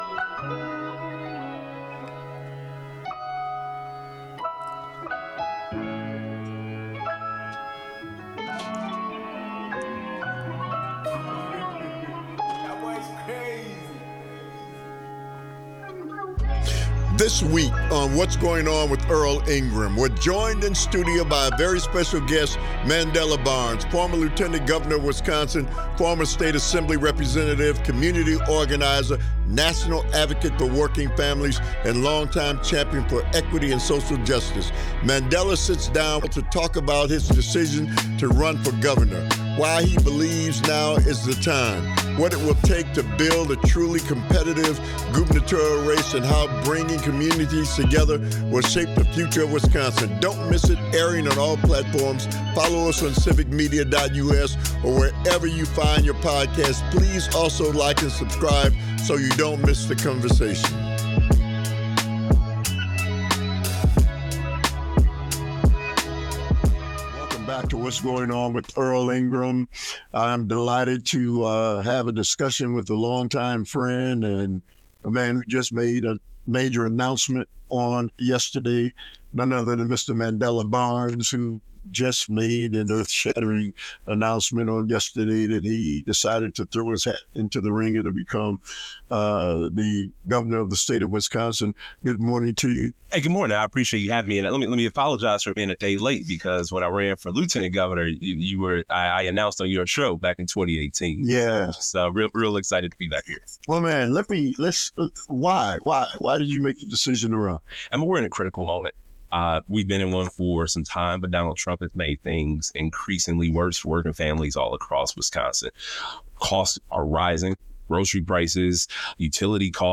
Guest: Mandela Barnes , Former Lieutenant Governor of Wisconsin, Previous State Assembly Representative.